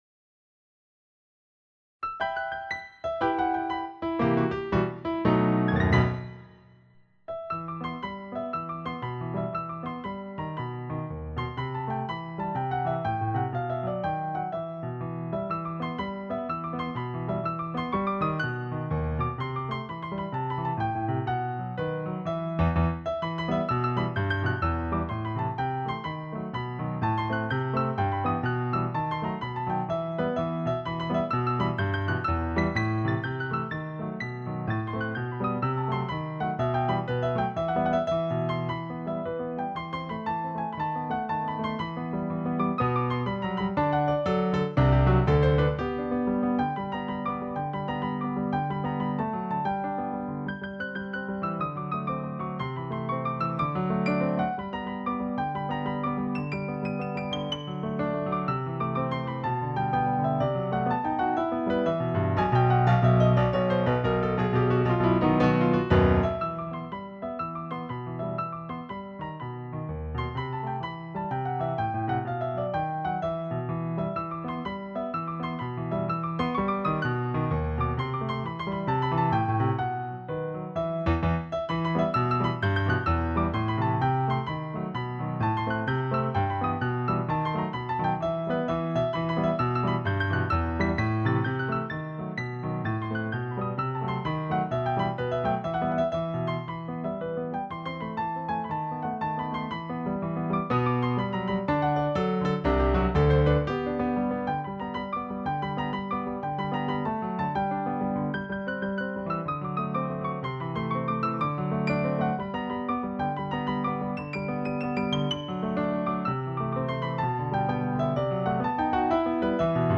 Tarantella